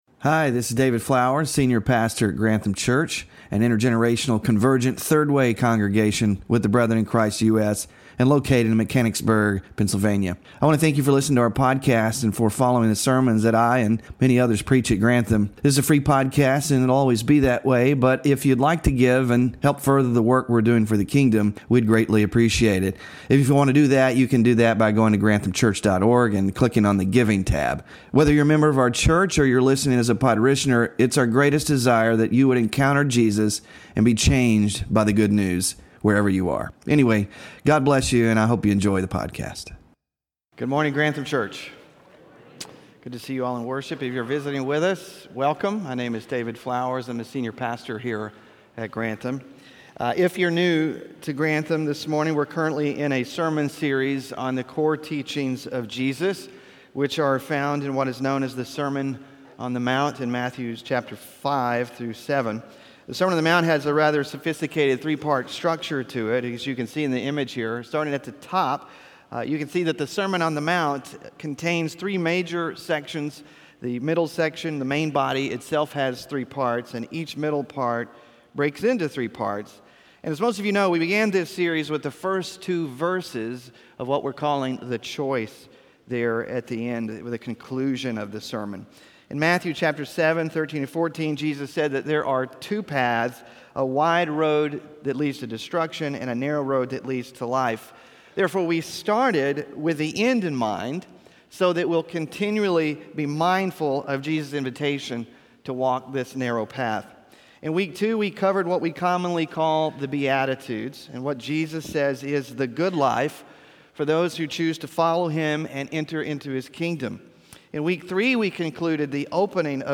Scripture Reading: Matthew 5:31-32; 19:1-12; 1 Corinthians 7:6-11